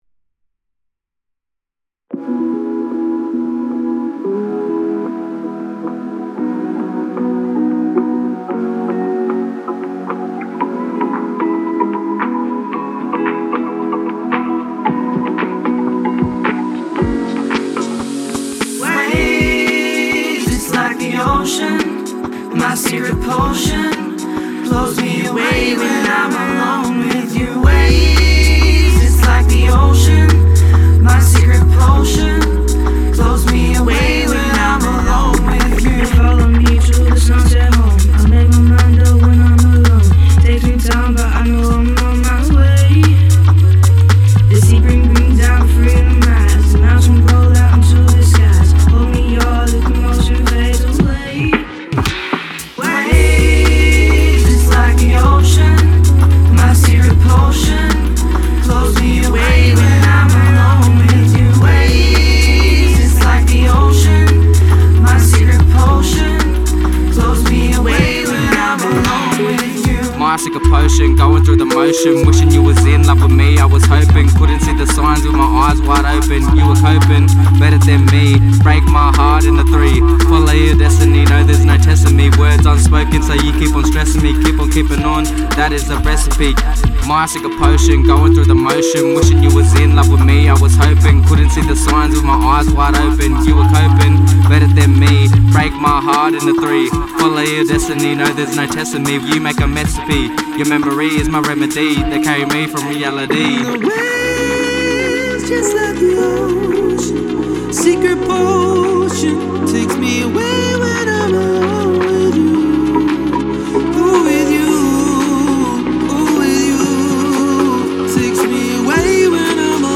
Group of young people singing into a microphone in a b & w ohoto
The journey culminated in four professionally recorded tracks, which were presented alongside corresponding artworks at the Place of Plenty Showcase.